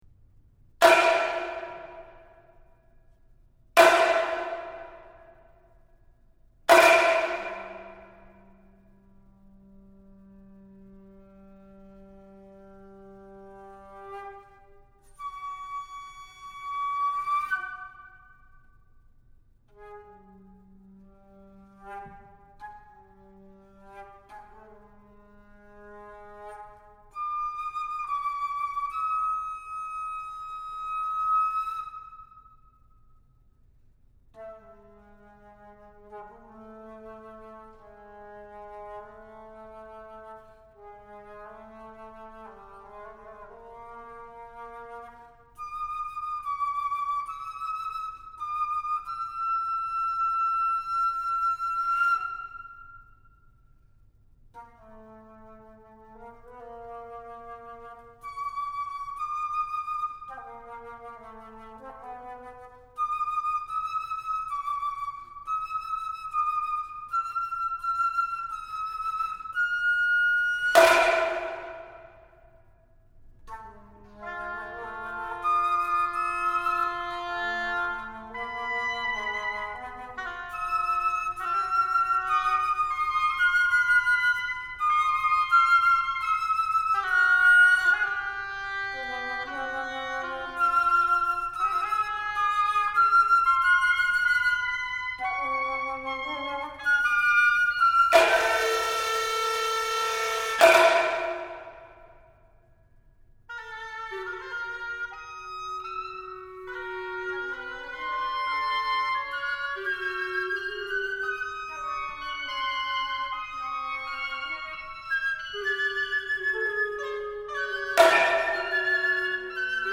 for Soprano and Chamber Orchestra (13 players)